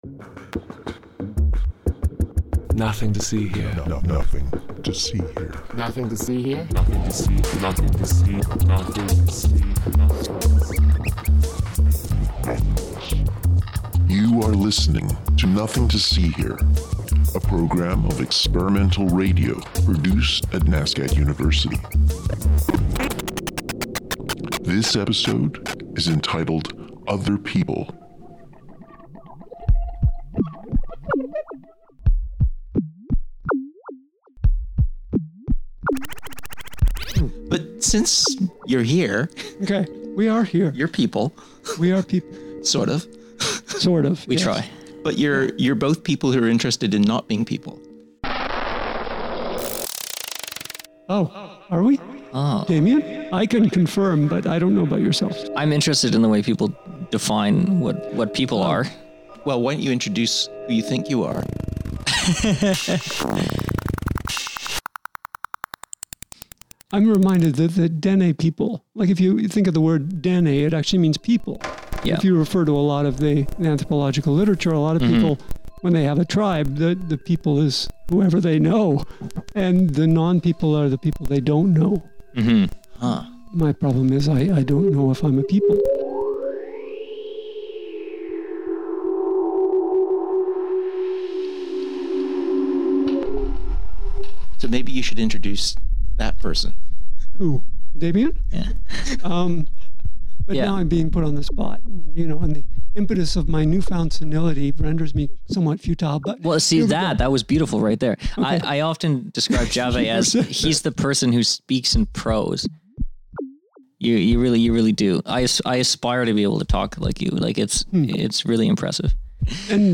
A conversation about many things including panpsychicism and what it's like to not be a person and. A digital poet and an anthropologist are huddled in a small dark room talking about whether they are ready to become other people.
Recorded at B21 at McGill University in Montreal. Nothing to See Here is a program of experimental spoken word radio produced at NSCAD University and broadcast every week at 10 a.m. on CKDU (88.1FM) in Halifax.